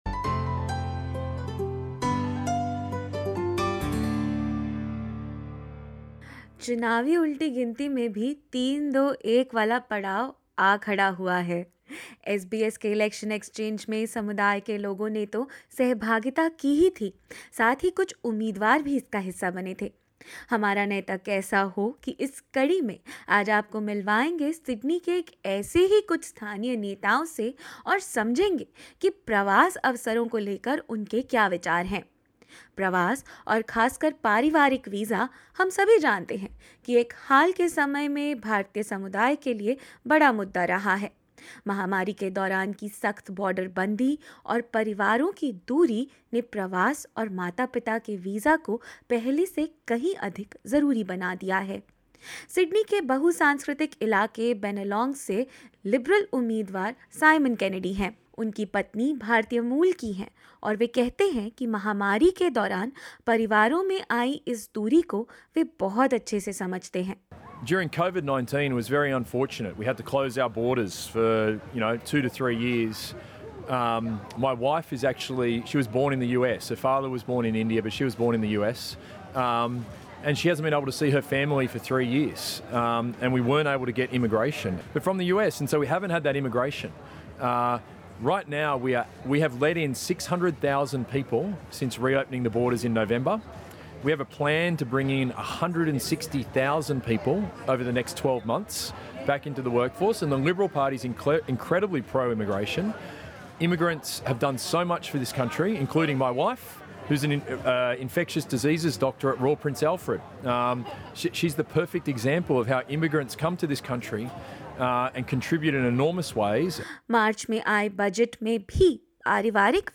SBS Election Exchange in Sydney saw several election candidates from different political parties. SBS Hindi has conducted an interview with the candidates in the third episode of its five-part 'Humara Neta Kaisa ho' series, which discusses the immigration challenges faced by the Indian community in Australia.